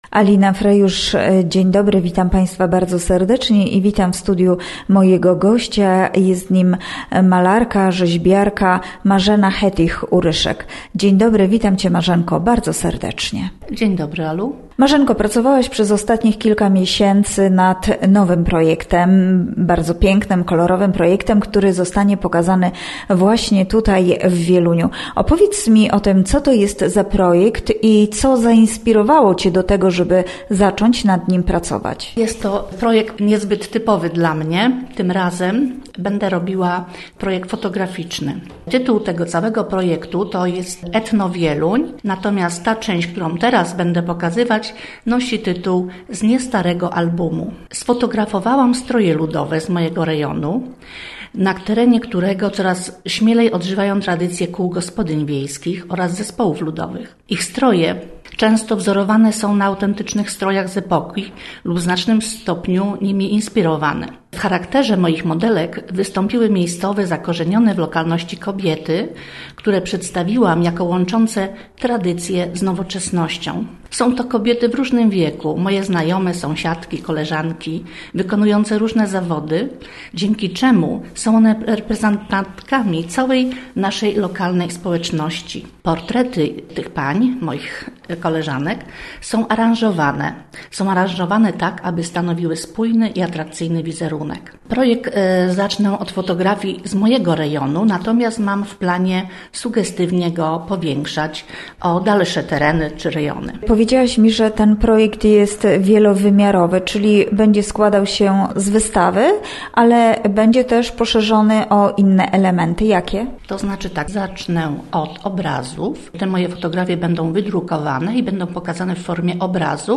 Gościem Radia ZW była malarka